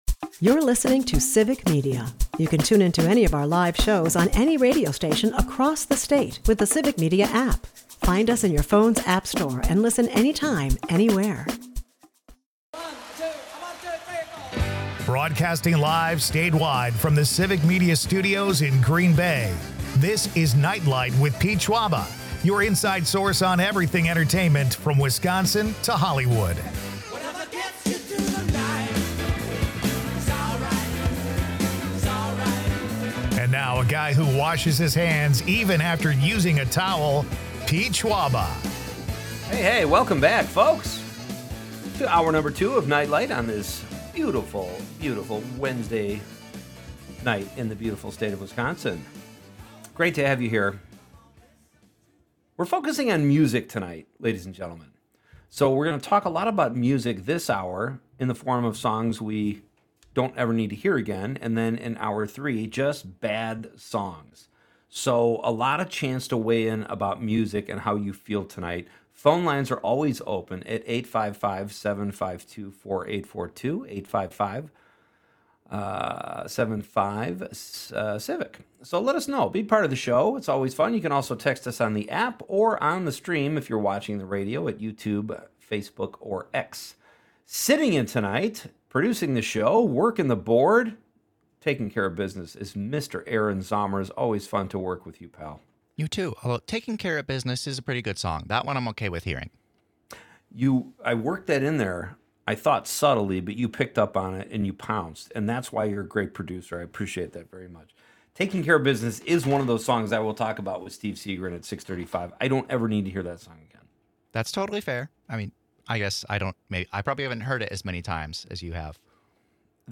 Not all of them are bad songs, but they're certainly overplayed. We take your texts with a laundry list of the most annoying things to hear on the radio.